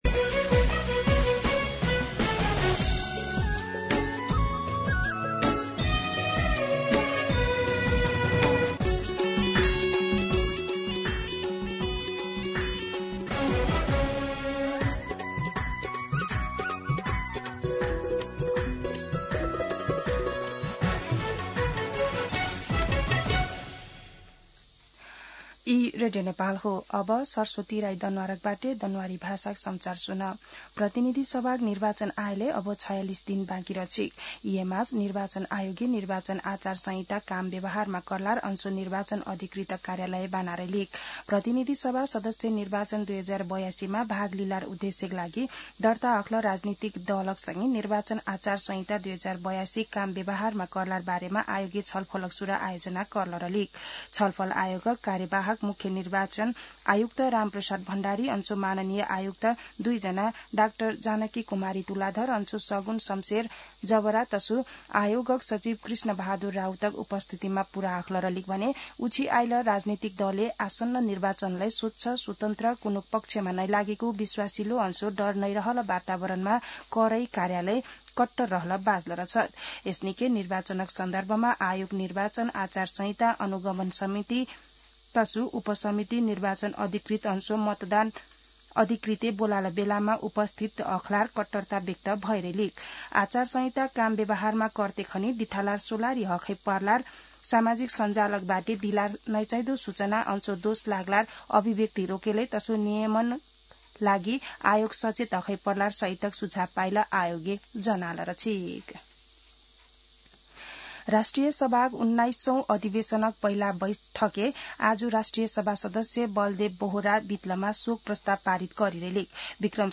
An online outlet of Nepal's national radio broadcaster
दनुवार भाषामा समाचार : ४ माघ , २०८२
Danuwar-News-04.mp3